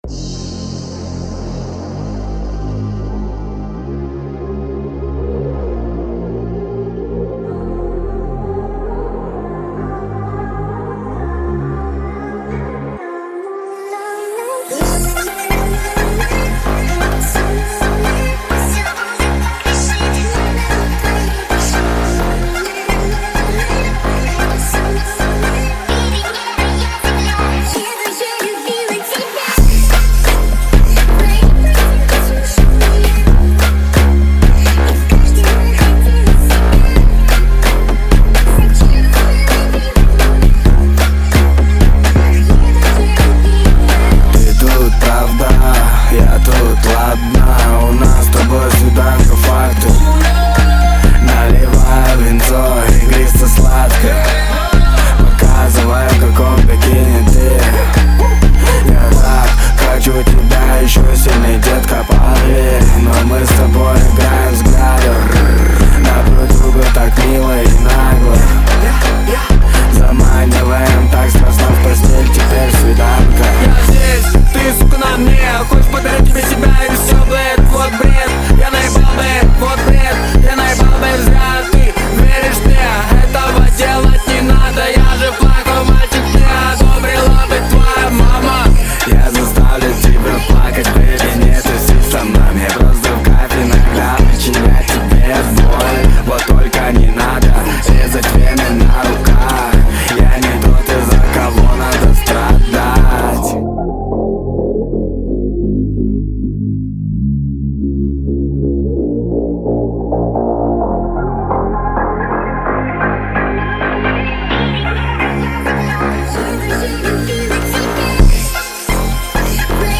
Поп